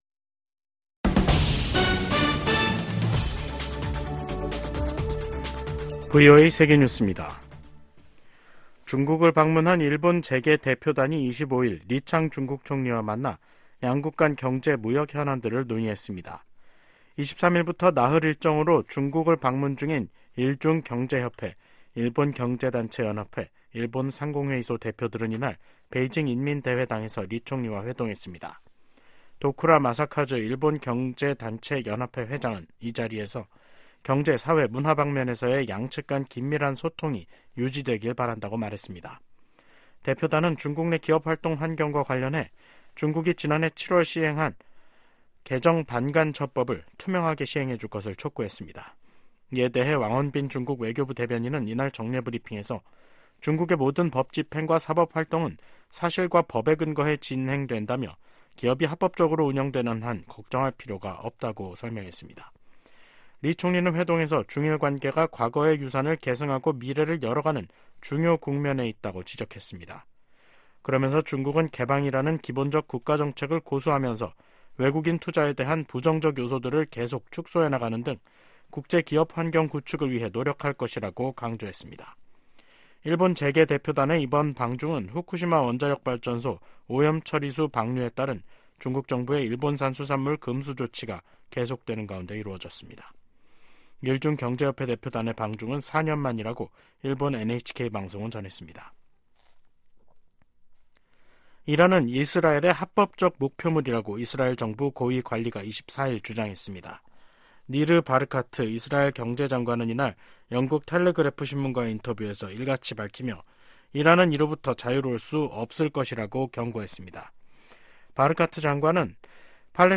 VOA 한국어 간판 뉴스 프로그램 '뉴스 투데이', 2024년 1월 25일 3부 방송입니다. 북한이 신형 전략순항미사일을 첫 시험발사했다고 밝혔습니다.